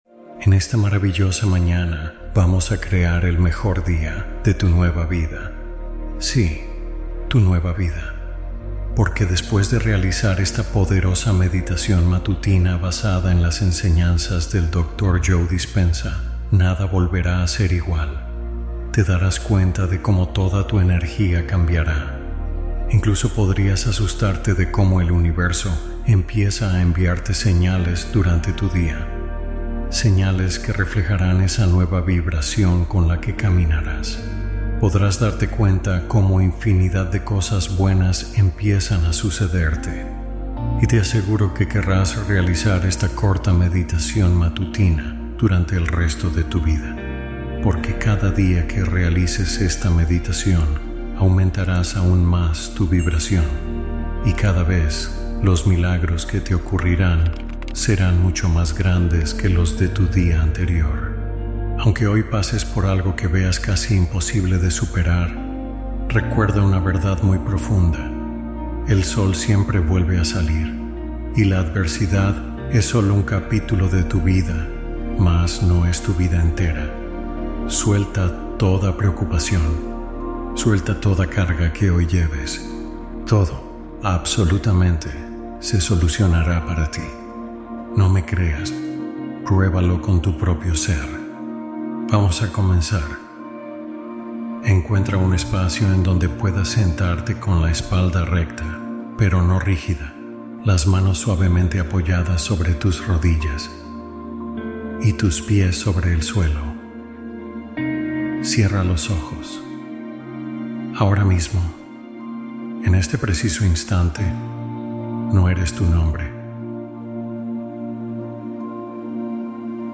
Los Milagros Comienzan Hoy con Esta Meditación Matutina